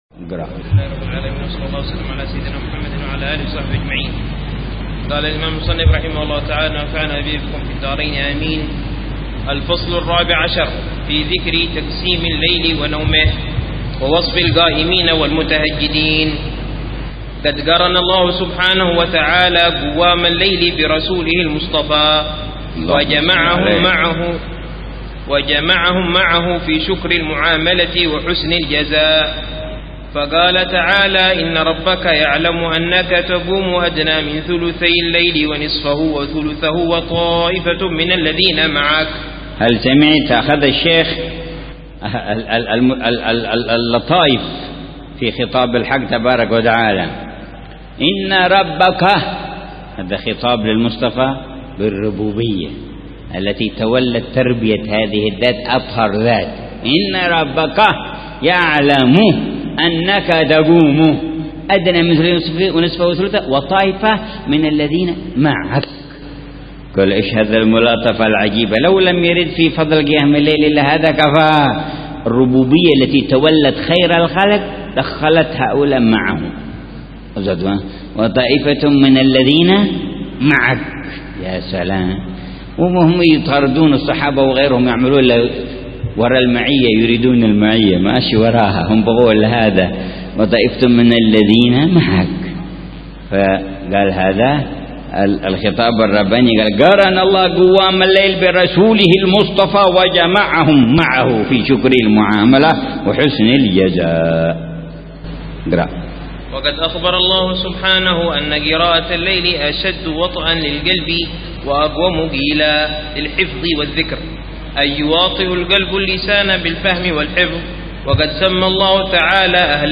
قراءة بتأمل وشرح لمعاني كتاب قوت القلوب للشيخ: أبي طالب المكي ضمن دروس الدورة التعليمية الخامسة عشرة بدار المصطفى 1430هجرية.